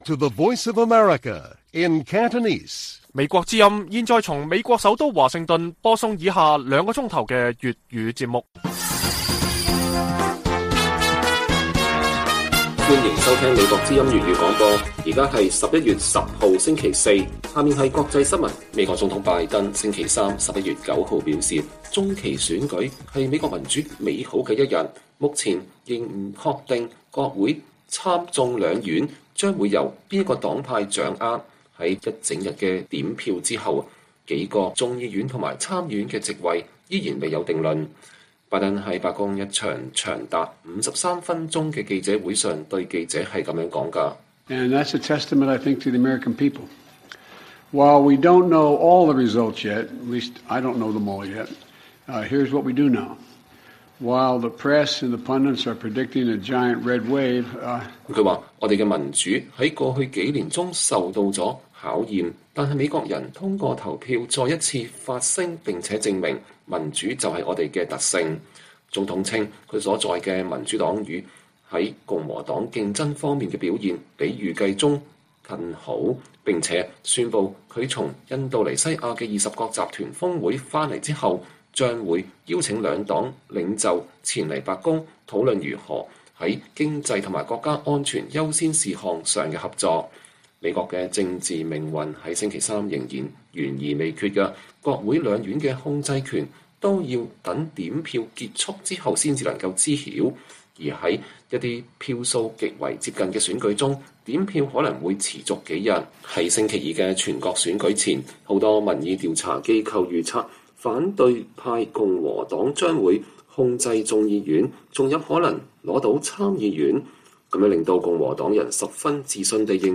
粵語新聞 晚上9-10點: 拜登讚揚中期選舉：民主是“我們的特性”